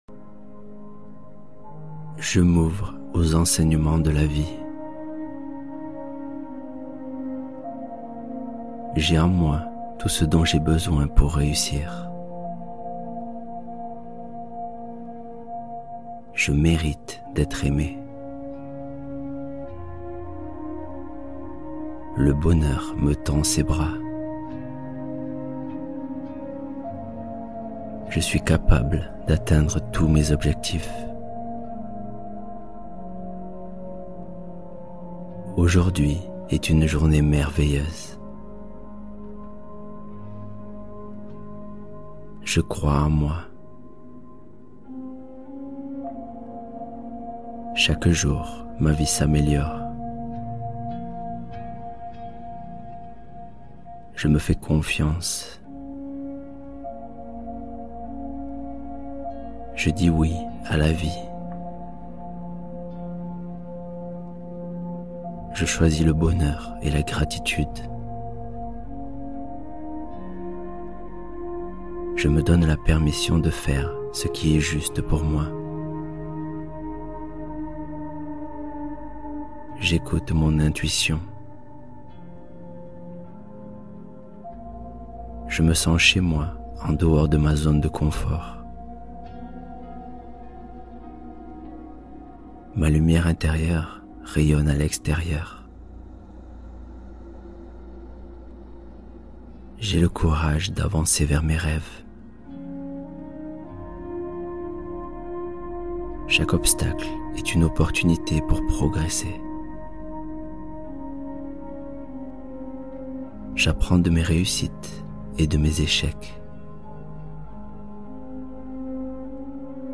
Épanouissement : Affirmations pour orienter son esprit vers la réussite